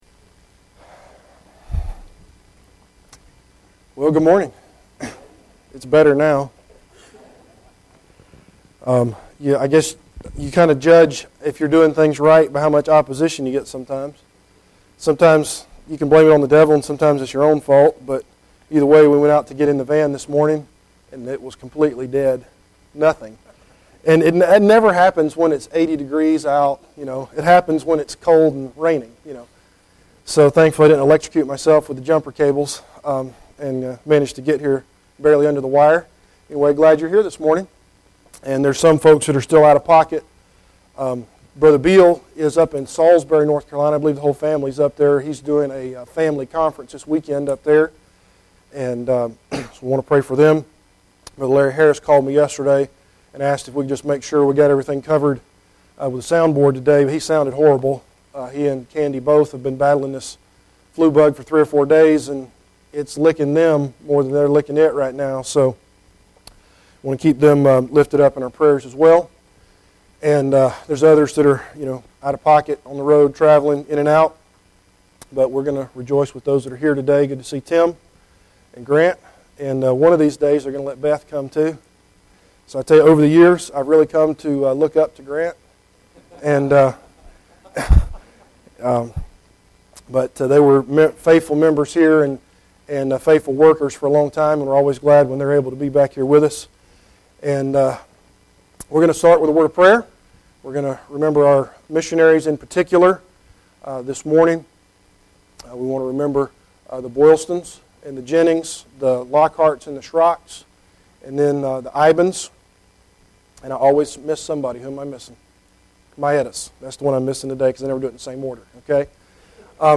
Service Type: Adult Sunday School Class